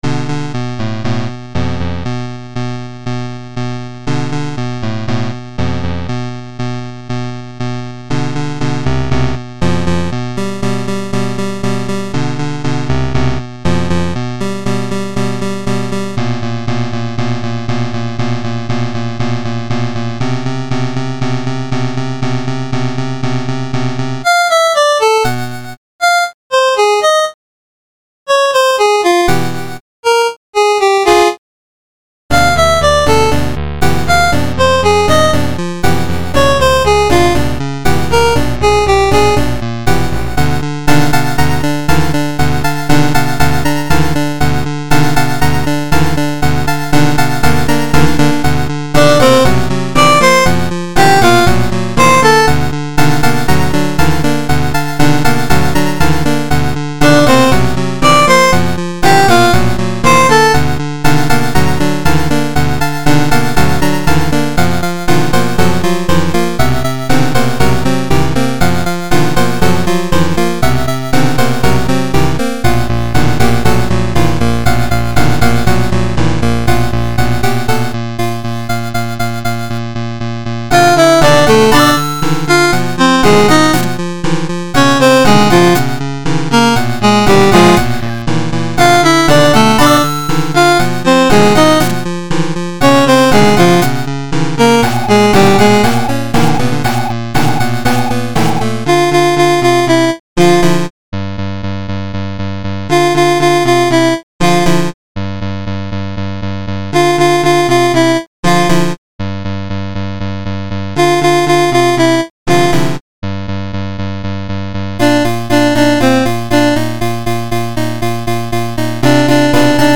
This track is a nostalgic edit.